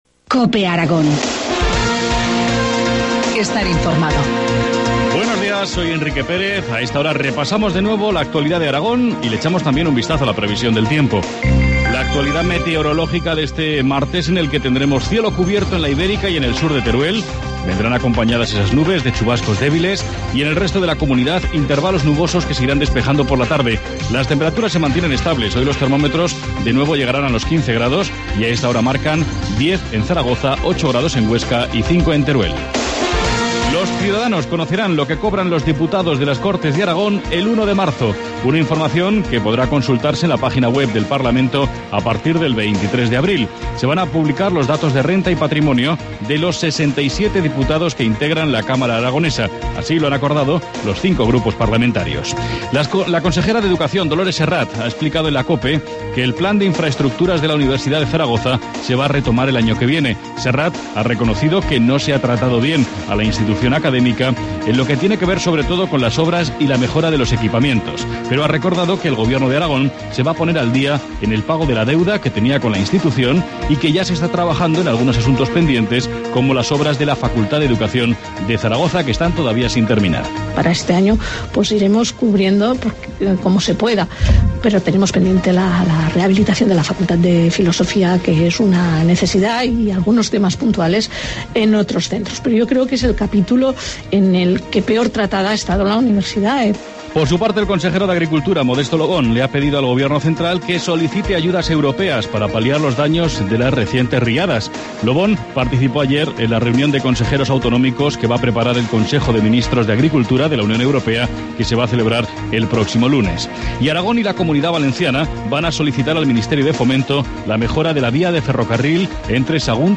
Informativo matinal, martes 19 de febrero, 8.25 horas